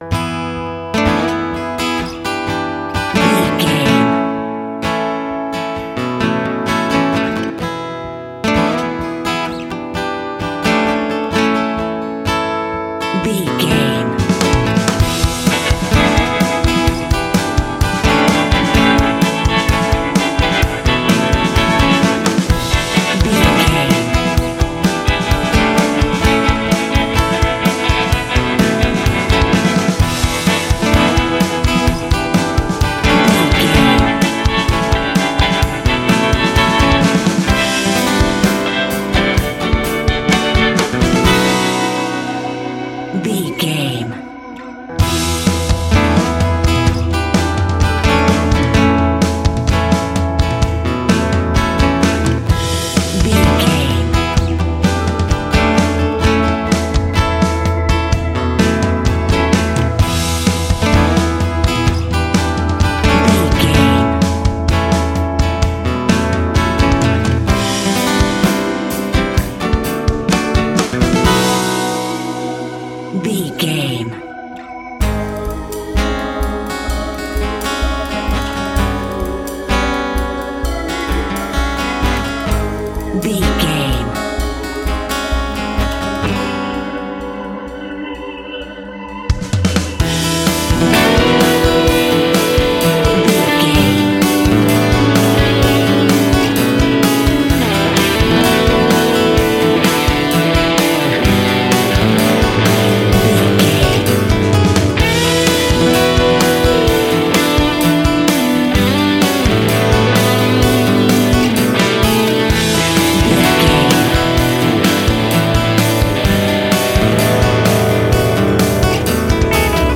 Ionian/Major
romantic
happy
acoustic guitar
bass guitar
drums